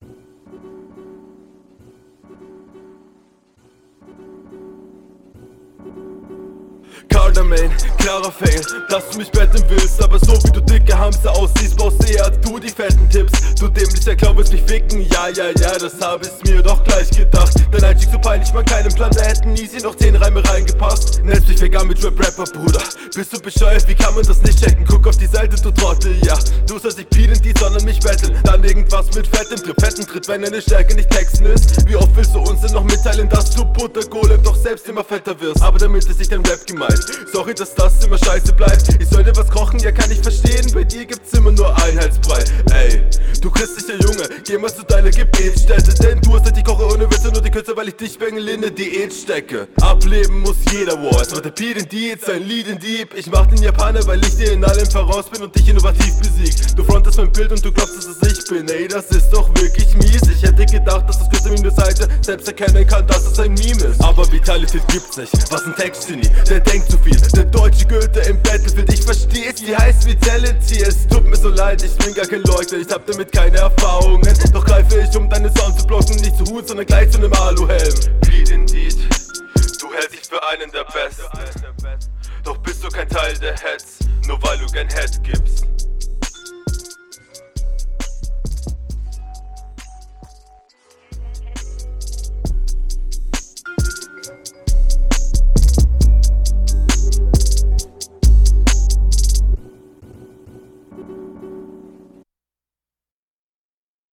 Akustisch n deutlicher Unterschied.